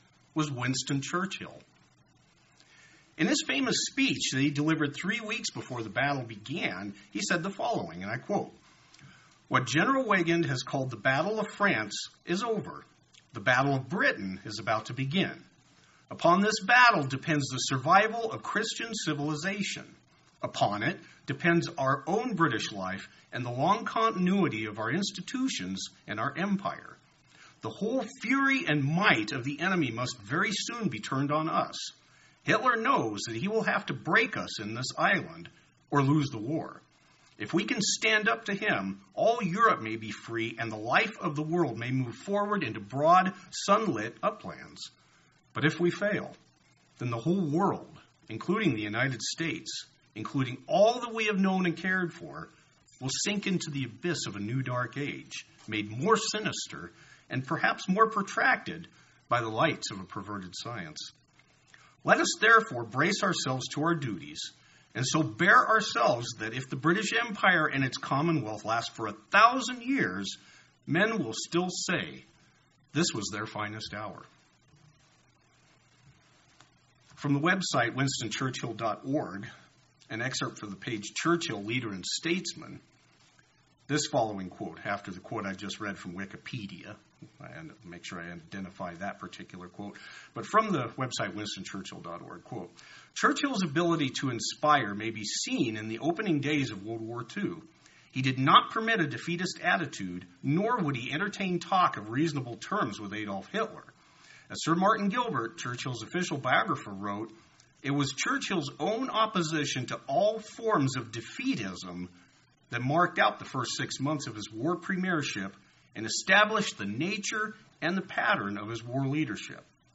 Sermons
Given in Central Oregon